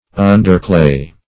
Search Result for " underclay" : The Collaborative International Dictionary of English v.0.48: Underclay \Un"der*clay`\, n. (Geol.) A stratum of clay lying beneath a coal bed, often containing the roots of coal plants, especially the Stigmaria .